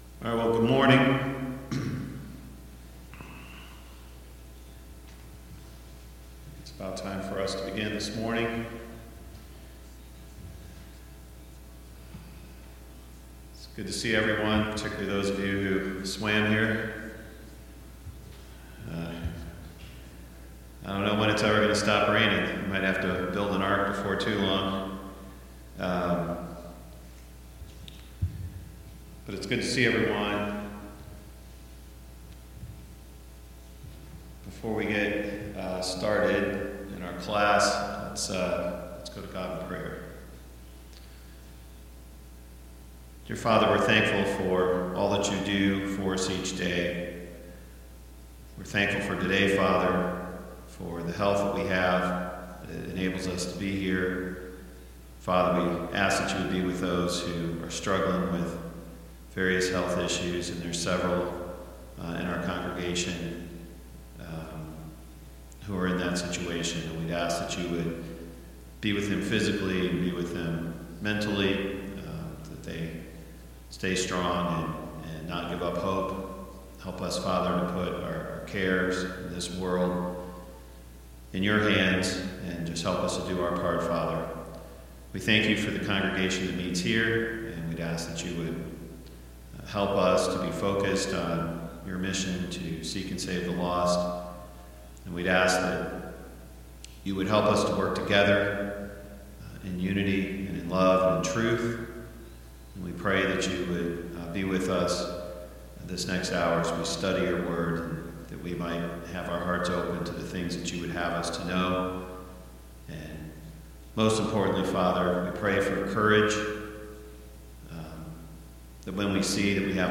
Service Type: Sunday Morning Bible Class Topics: The Need for Unity in the church , Unity in the Church « Paul & Barnabas sent on their first missionary trip